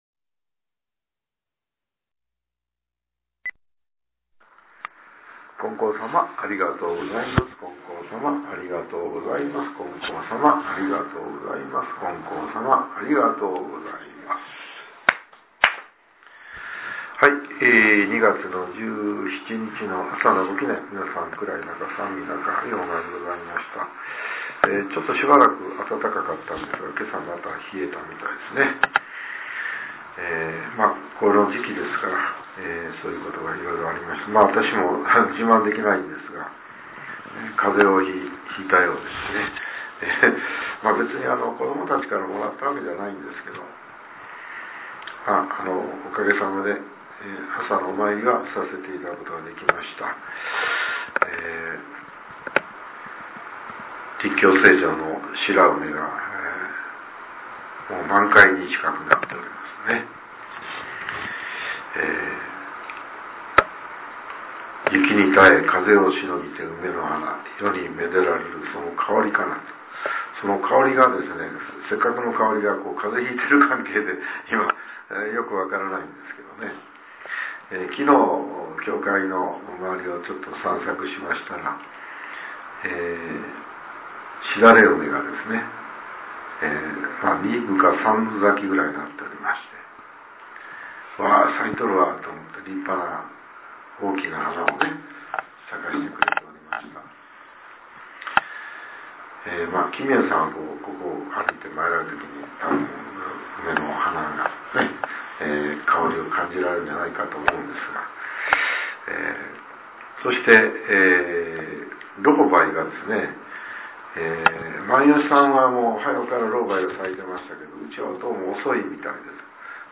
令和８年２月１７日（朝）のお話が、音声ブログとして更新させれています。